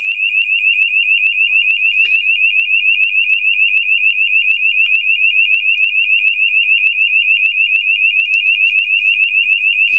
Immediate-evacuation.mp3